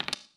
surface_wood_tray3.mp3